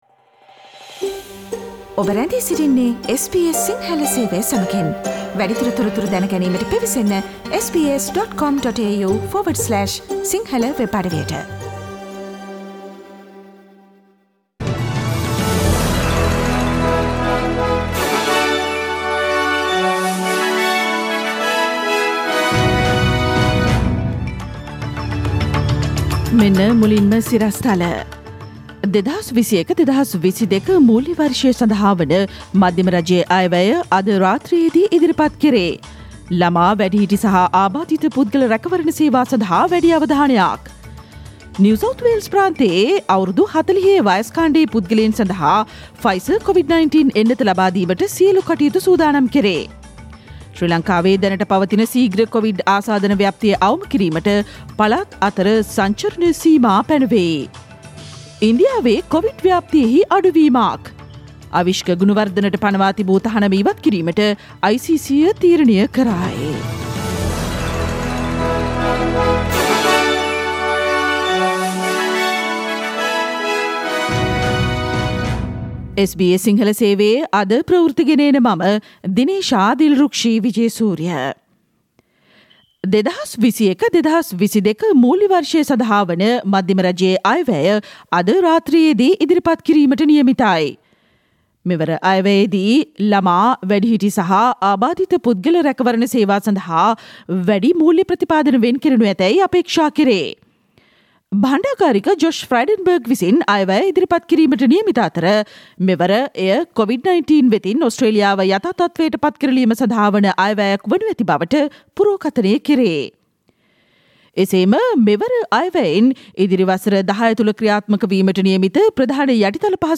සවන්දෙන්න, ඕස්ට්‍රේලියාවේ සහ ශ්‍රී ලංකාවේ අලුත්ම පුවත්, විදෙස් තොරතුරු සහ ක්‍රීඩා පුවත් රැගත් SBS සිංහල සේවයේ 2021 මැයි මස 11 වන අඟහරුවාදා වැඩසටහනේ ප්‍රවෘත්ති ප්‍රකාශයට